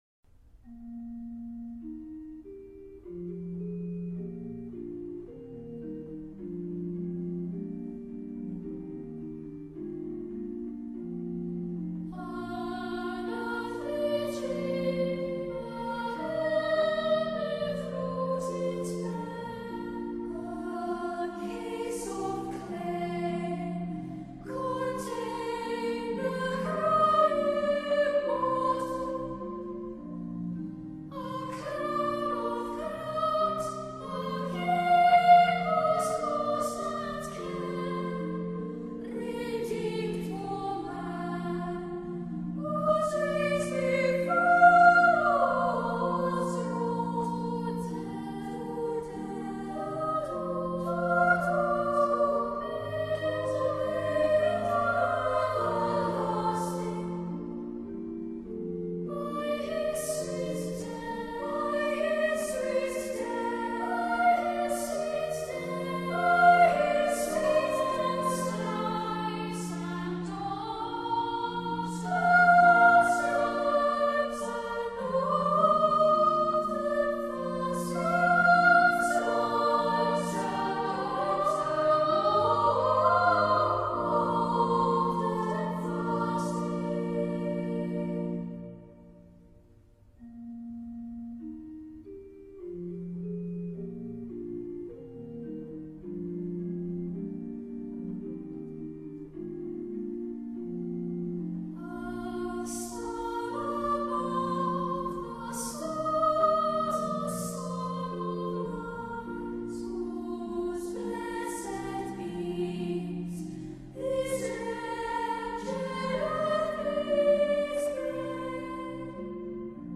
choir.mp3